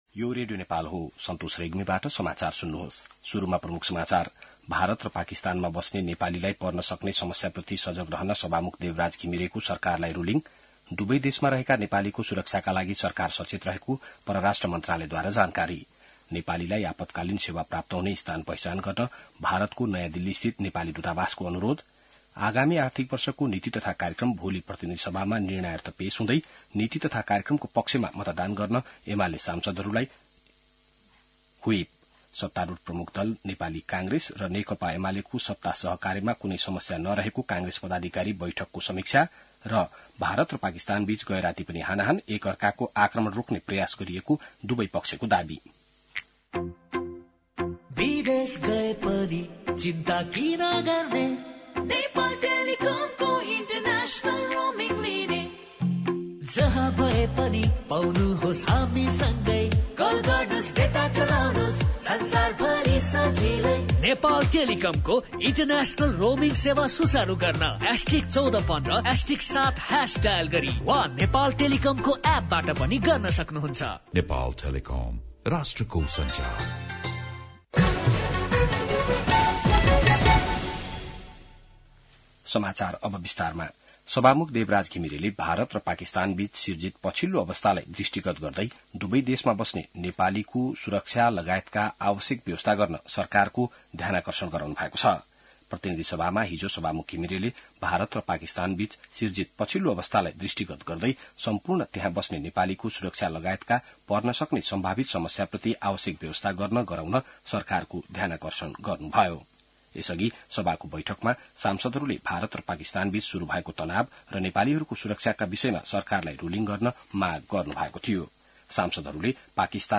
बिहान ७ बजेको नेपाली समाचार : २७ वैशाख , २०८२